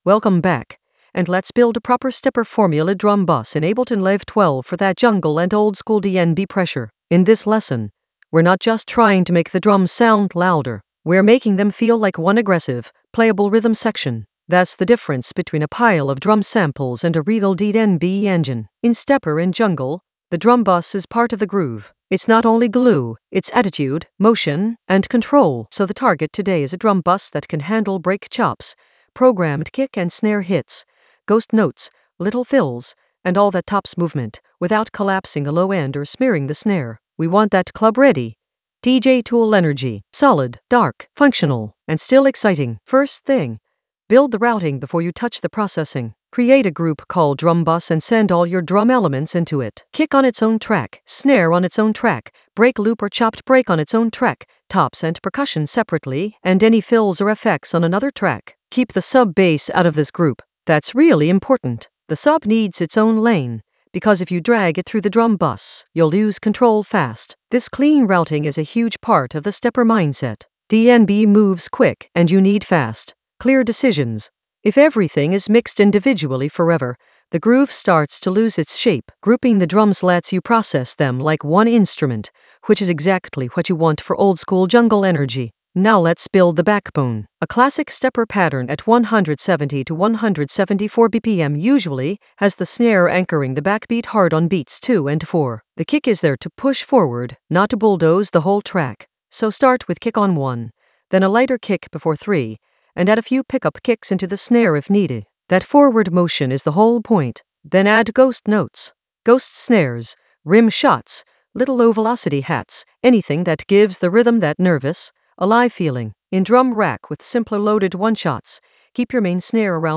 Narrated lesson audio
The voice track includes the tutorial plus extra teacher commentary.
An AI-generated intermediate Ableton lesson focused on Stepper formula: drum bus design in Ableton Live 12 for jungle oldskool DnB vibes in the DJ Tools area of drum and bass production.